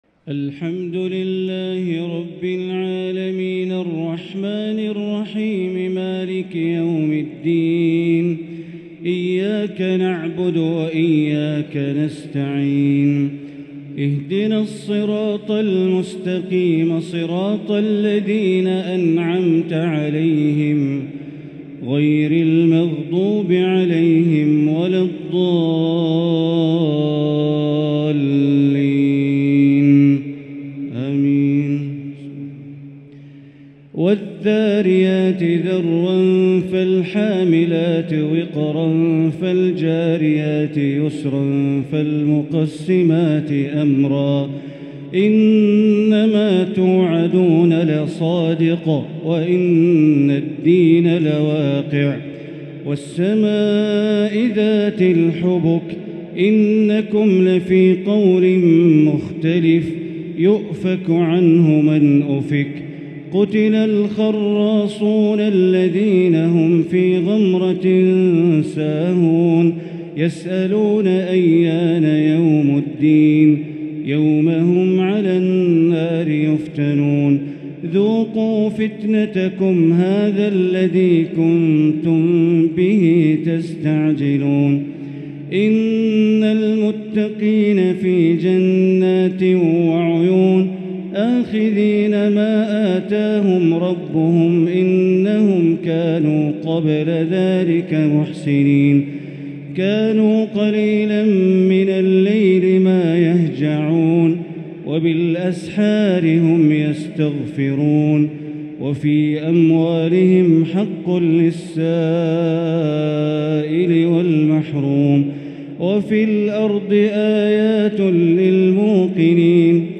تهجد ليلة 27 رمضان 1444هـ من سورة الذاريات إلى سورة الواقعة | Tahajjud 27st night Ramadan 1444H from Surah Adh-Dhariyat from Surah Al-Waqia > تراويح الحرم المكي عام 1444 🕋 > التراويح - تلاوات الحرمين